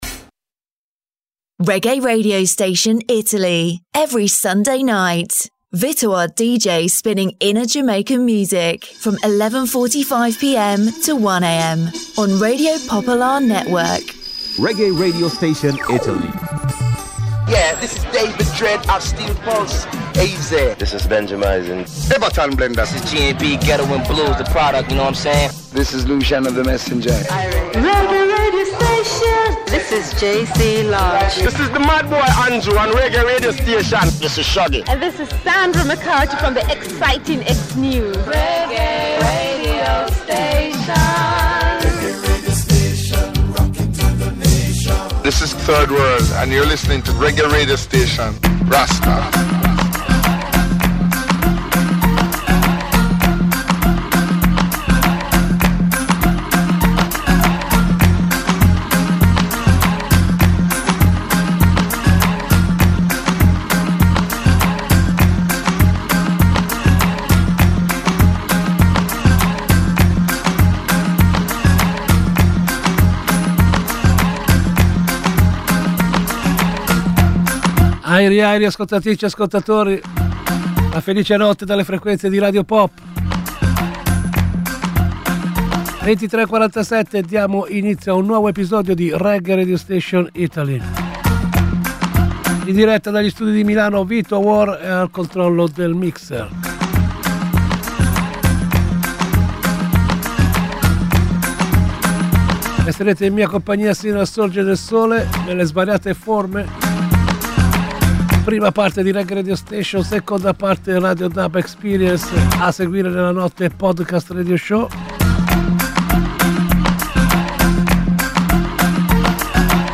A ritmo di Reggae Reggae Radio Station accompagna discretamente l’ascoltatore in un viaggio attraverso le svariate sonorità della Reggae Music e...